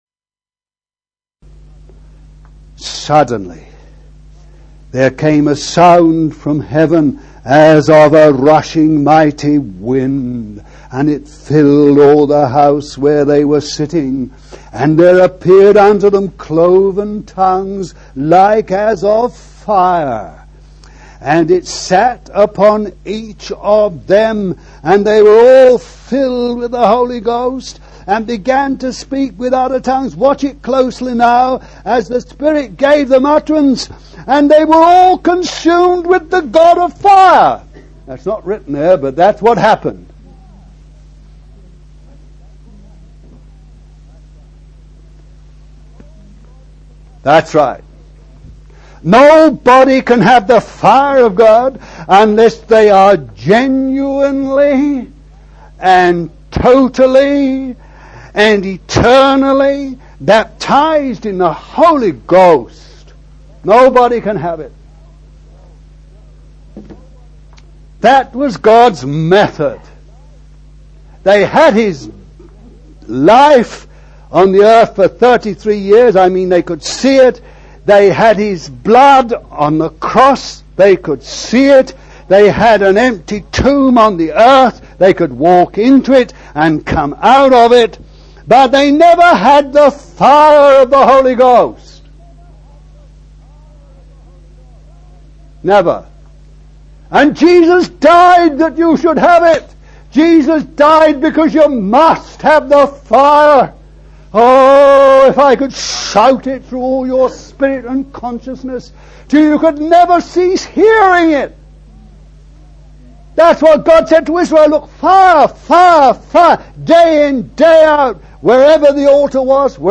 No 2 of 6 messages on the Fire of God, given over a period of 4 years at Rora House CF, Devon; Devonshire Rd CF Liverpool; the Longcroft CF, Wirral